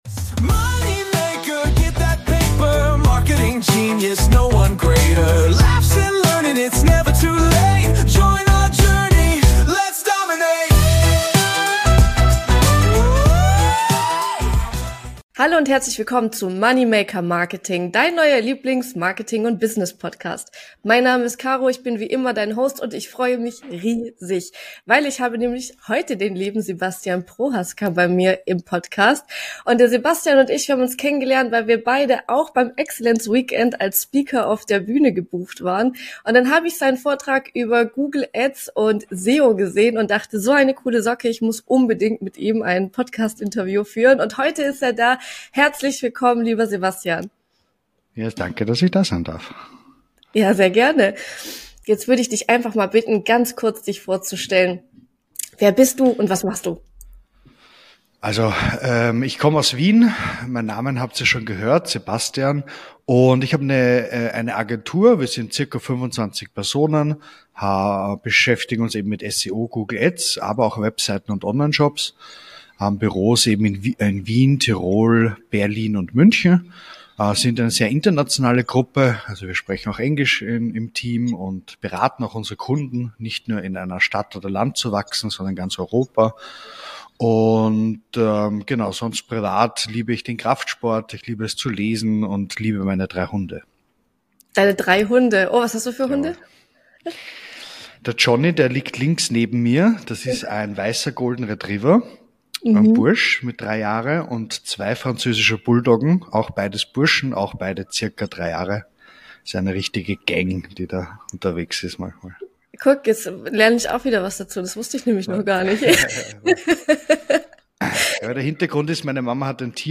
MMM 7: DIE KUNST DER SUCHMASCHINENOPTIMIERUNG: EIN EXPERTENGESPRÄCH